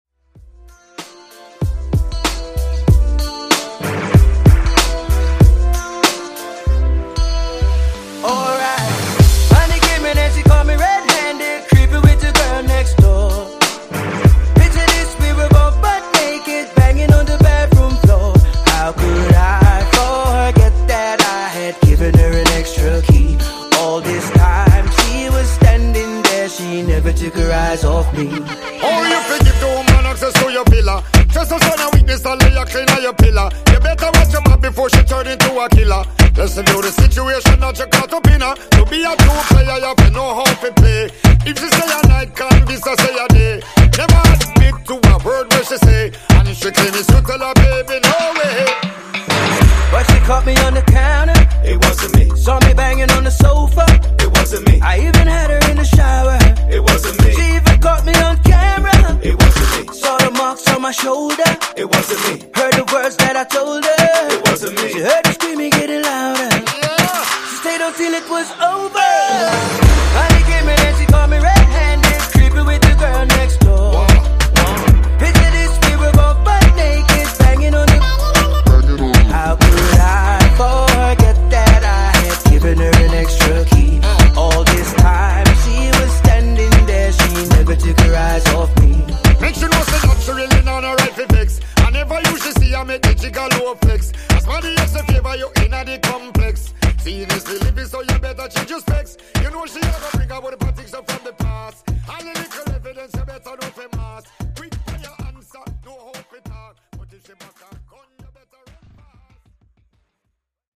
Genre: RE-DRUM
Clean BPM: 80 Time